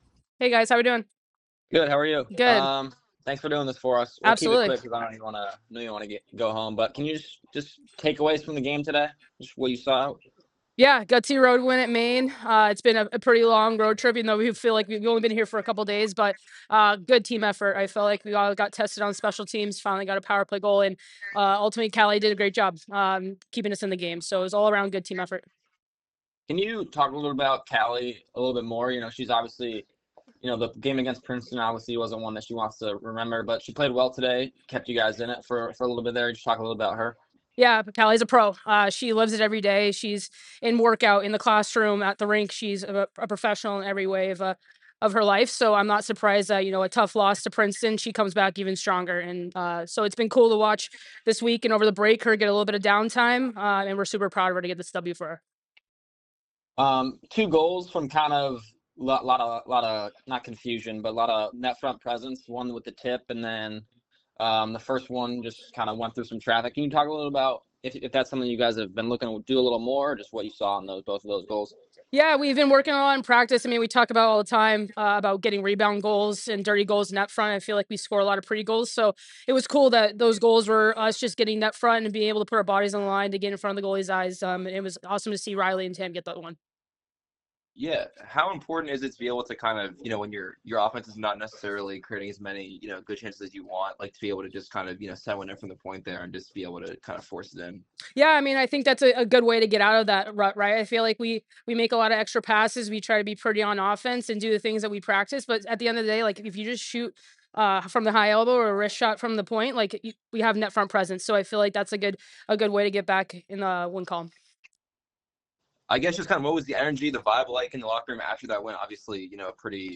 Maine Postgame Interview